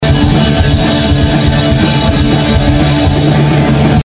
men var du tvungen att ta en fil med så dåligt ljud som möjligt för att maskera skillnaden?
Det är ett klipp från en liveupptagning med en handhållen videokamera, eller nåt, från en punkkonsert.